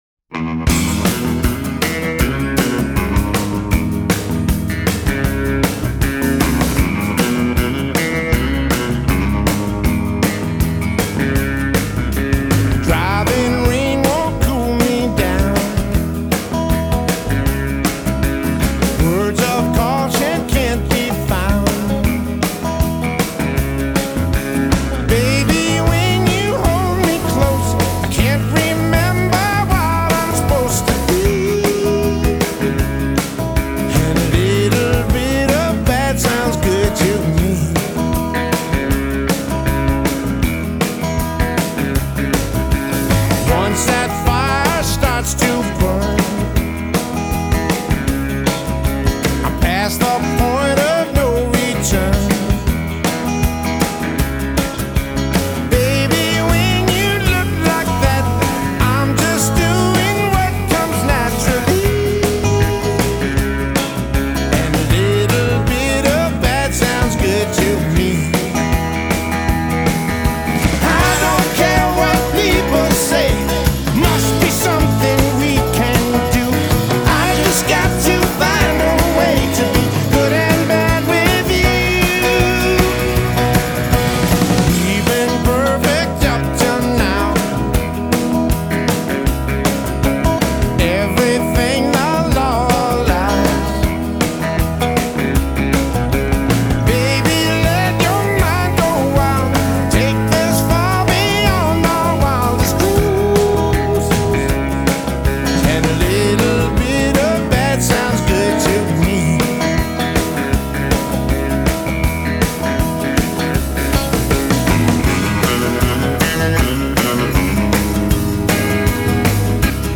rumbly guitar